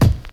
0201 DR.LOOP.wav